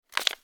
paper_pickup1.wav